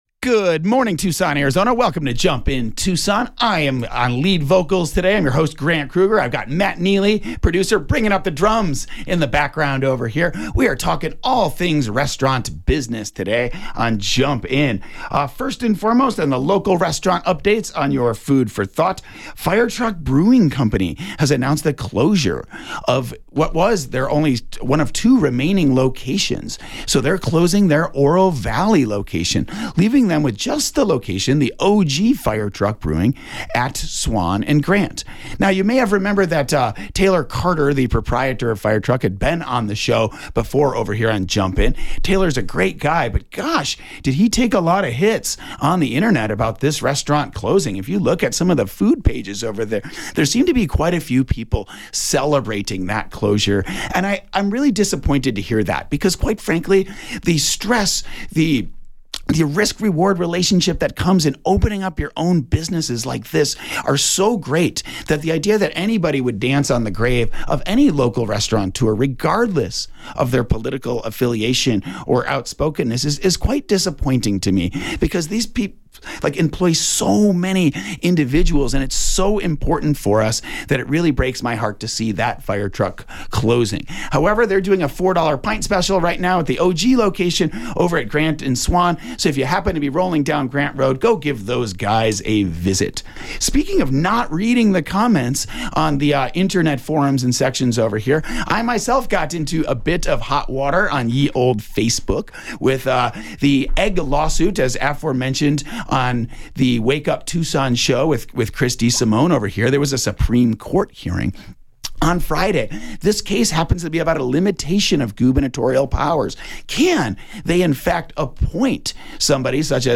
Based on the 8/21/24 Jump In Tucson Show on KVOI-1030AM in Tucson, AZ.